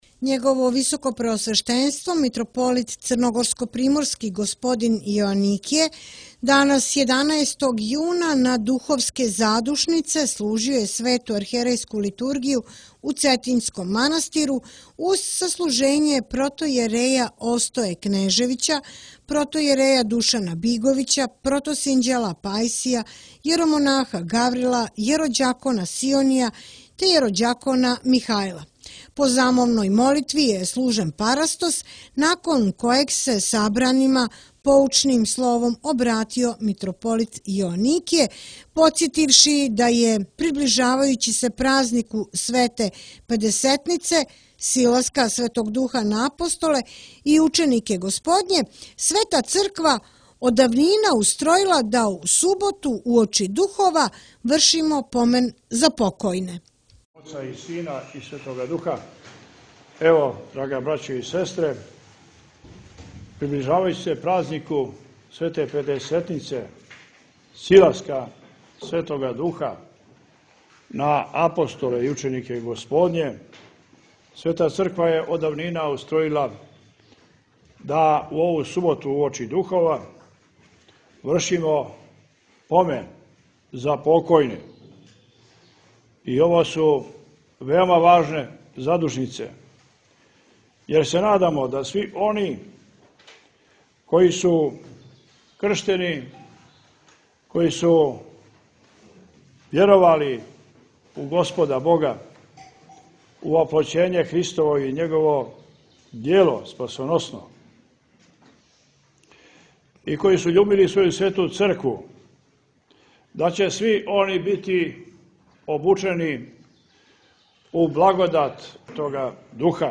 Mitropolit Joanikije na Duhovske zadušnice bogoslužio u Cetinjskom manastiru
Njegovo visokopreosveštenstvo Mitropolit crnogorsko primorski g. Joanikije danas, 11. juna, na Duhovske zadušnice, služio je Svetu arhijerejsku liturgiju u Cetinjskom manastiru, […]